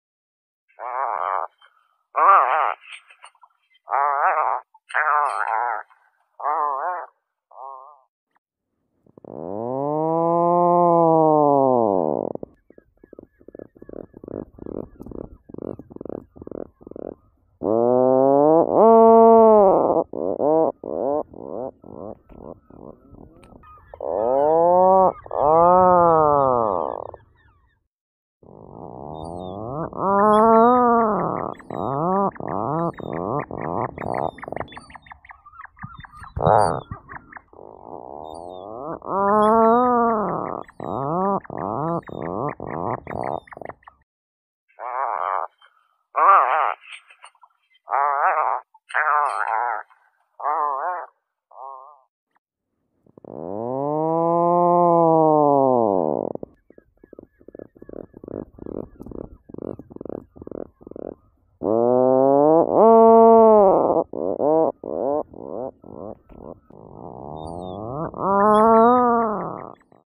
Atlantic Puffins
Atlantic-puffin-sound-puffin-call.mp3